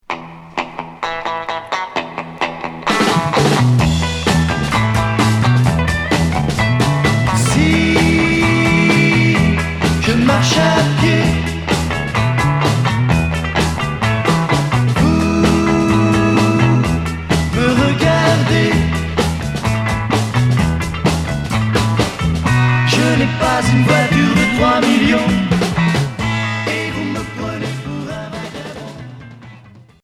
Mod 60's